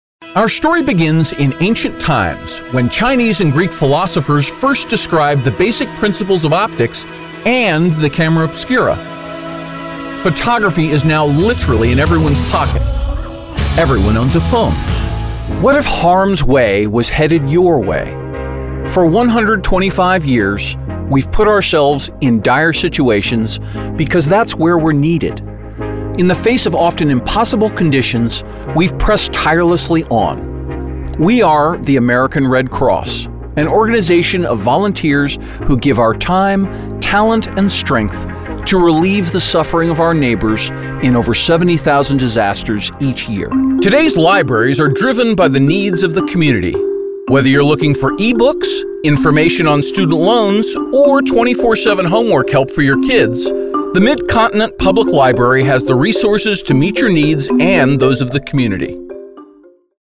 Upbeat, approachable voices that match Vinted’s fun and friendly style.